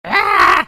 jumpsound.mp3